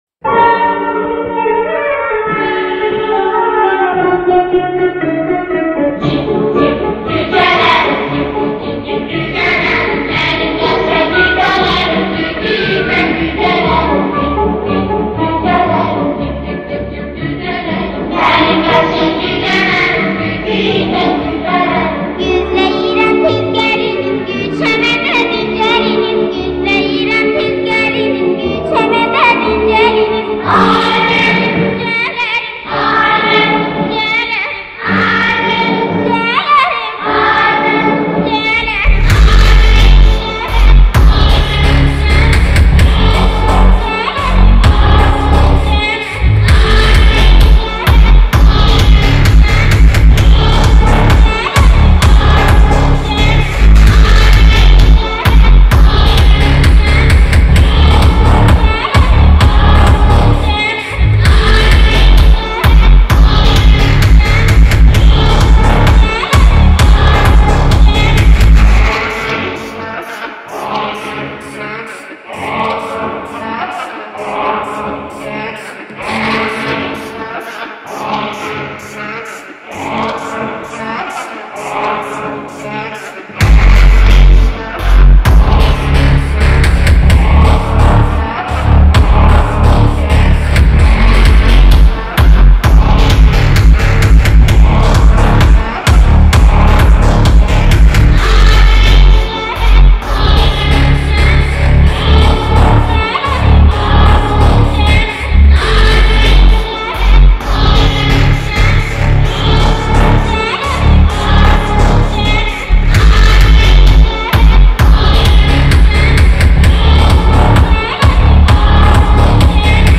в формате 8D Audio